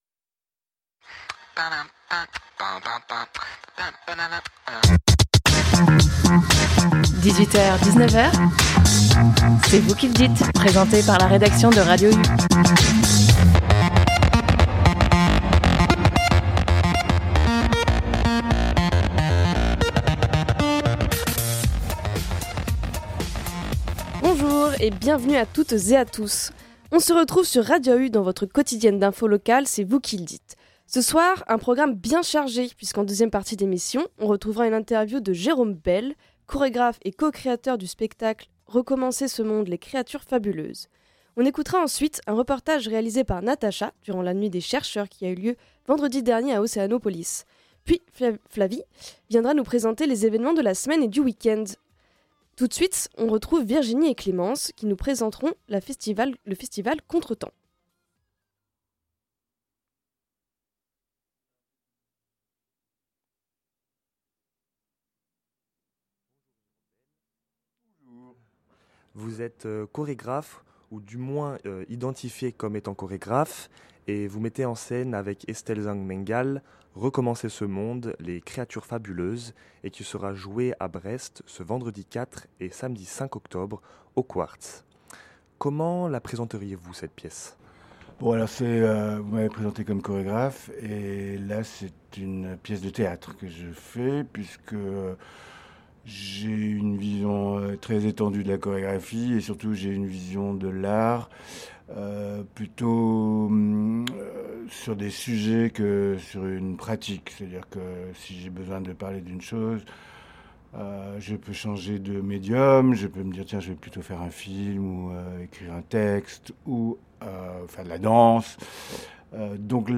Dans cette émission, tout d’abord une interview de Jérôme Bel qui présente sa nouvelle pièce de théâtre au Quartz vendredi, centrée autour du thème de l’écologie.
Le temps sera ensuite plus calme avec un reportage de la Nuit des chercheurs qui s’est déroulée le week-end dernier à Océanopolis.